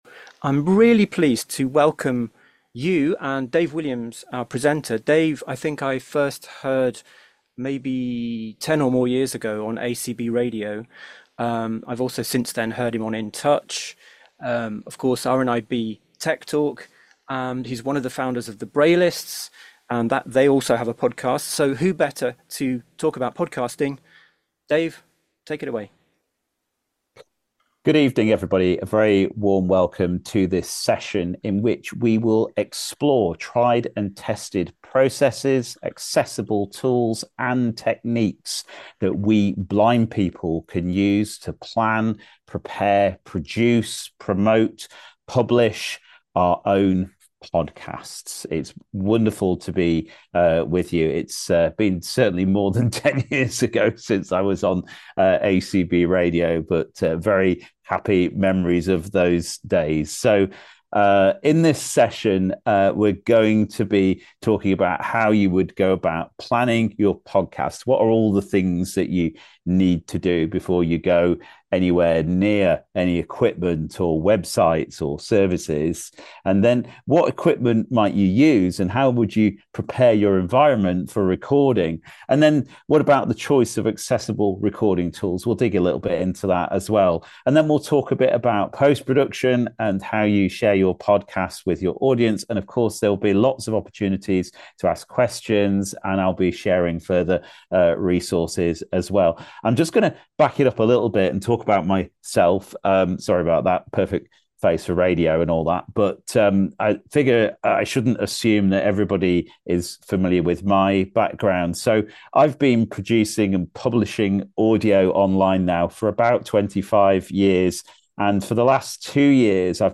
TAVIP Masterclass on Accessible Podcast Production